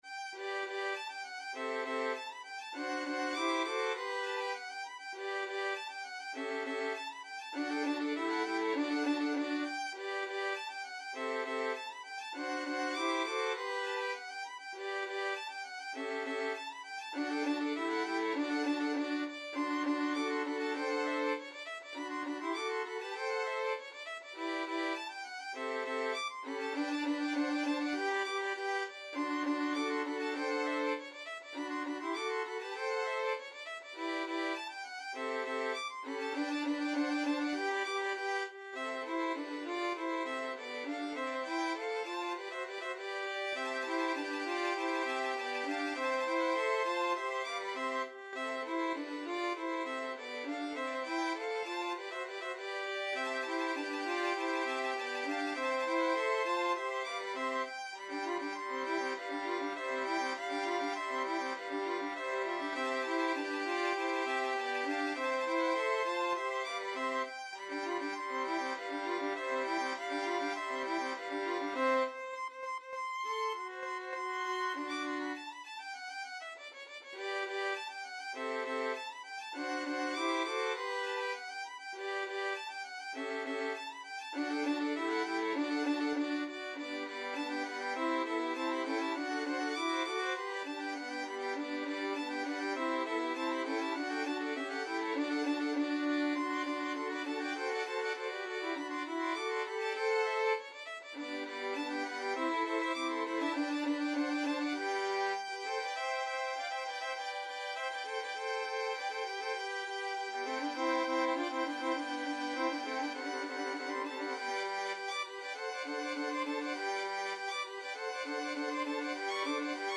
Free Sheet music for Violin Quartet
Violin 1Violin 2Violin 3Violin 4
This piece is uniformly light and playful in character to reflect his interest in musical clock in the nineteenth century.
G major (Sounding Pitch) (View more G major Music for Violin Quartet )
2/4 (View more 2/4 Music)
Classical (View more Classical Violin Quartet Music)
beethoven_musical_clock_4VLN.mp3